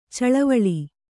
♪ caḷavaḷi